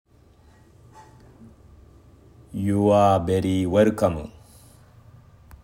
通常カタカナ ユア　ベリー　ウェルカム！